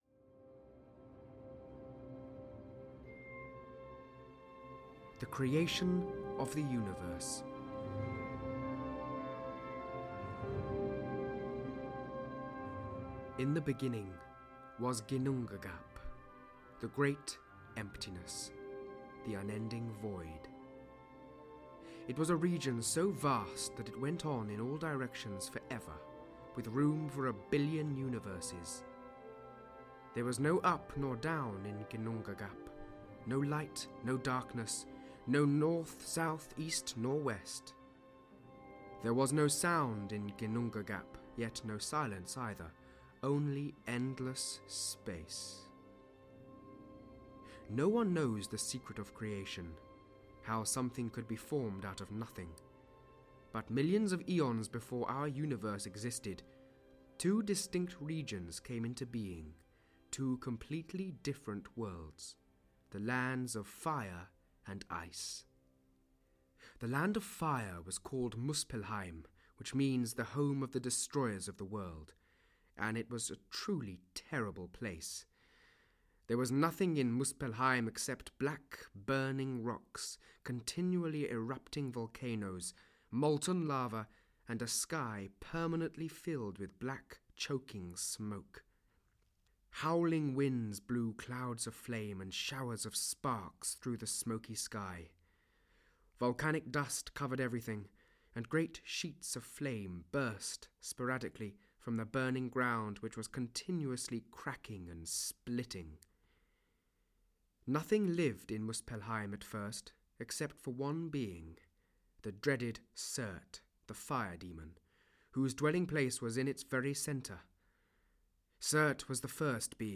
Ukázka z knihy
These retellings of the old tales are given extra dramatic perspective by the music of Mahler, Grieg, Smetana.